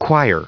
choir_en-us_recite_stardict.mp3